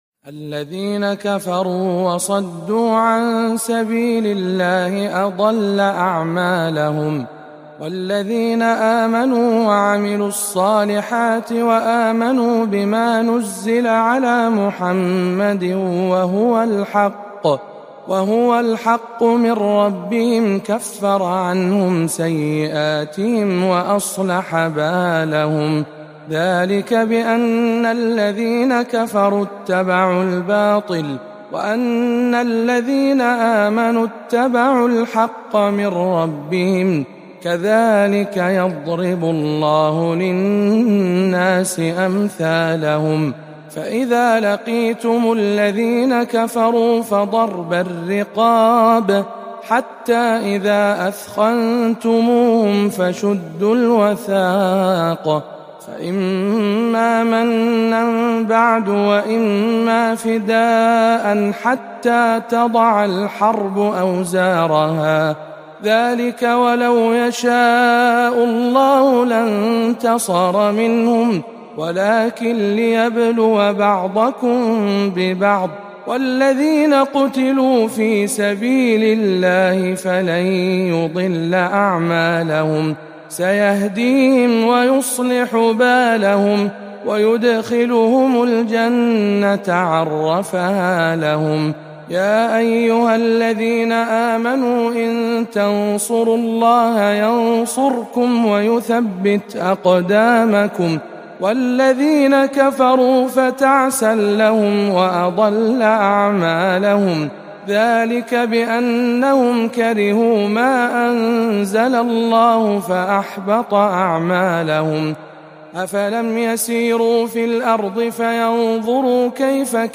سورة محمد بجامع معاذ بن جبل بمكة المكرمة